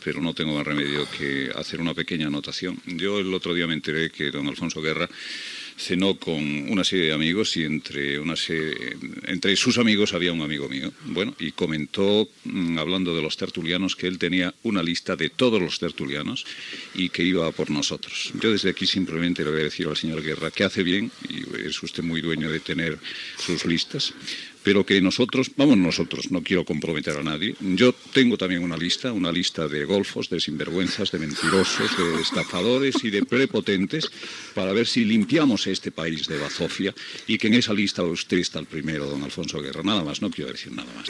Comentari per expressar el desacord sobre les concessions d'emissores d'FM per part del govern espanyol.
Info-entreteniment